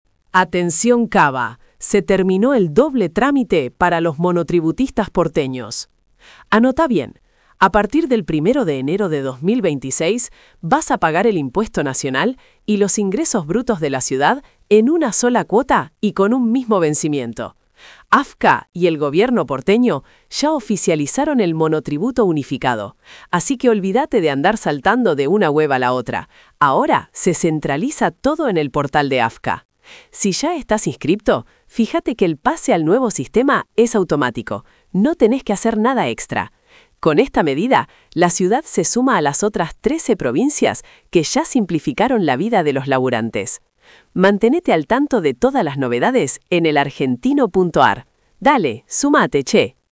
— 🎙 Resumen de audio generado por IA.